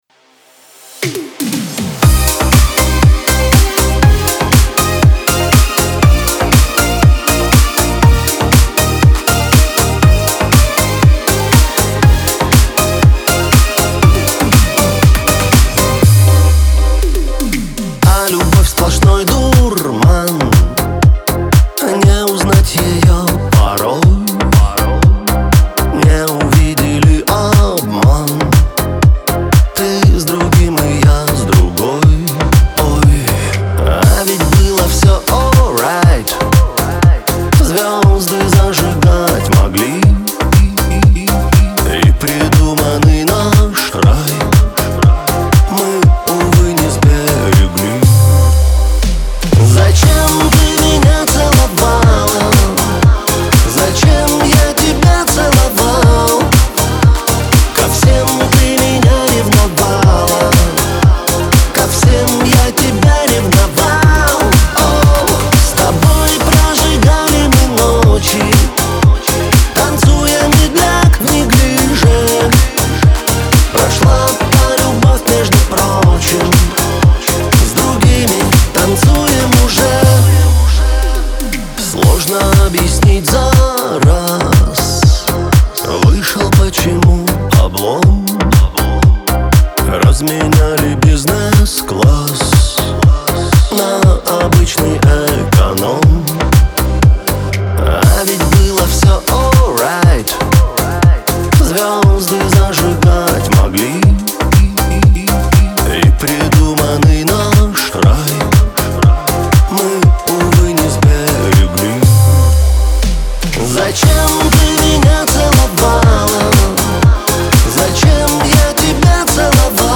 диско
pop
эстрада